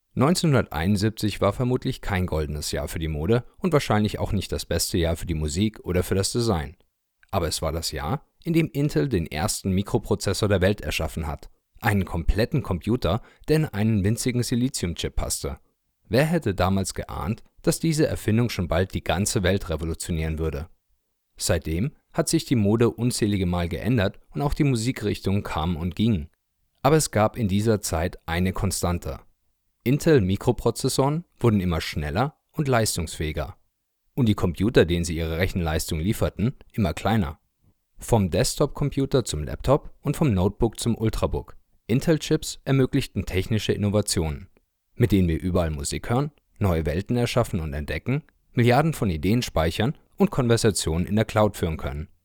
German native speaker with more than 7 years of professional Voice Over experience for all your audio needs.
Sprechprobe: Werbung (Muttersprache):